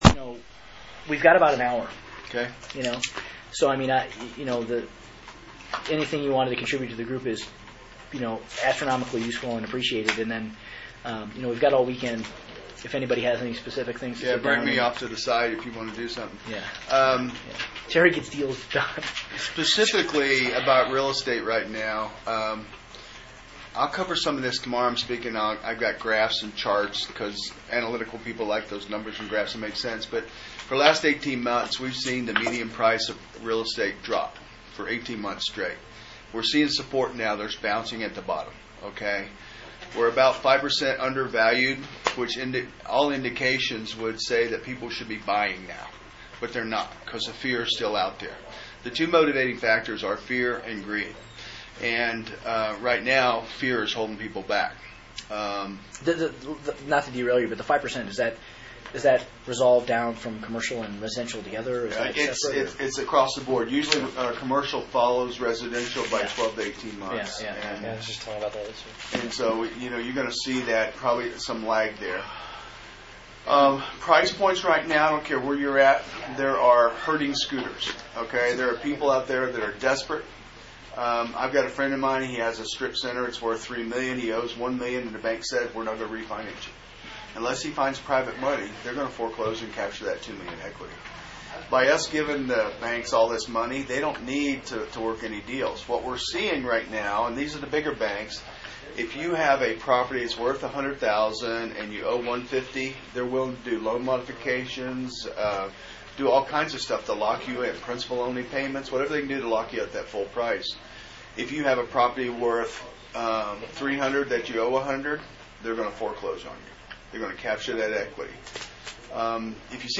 Category: Live Meetings - Inner Circle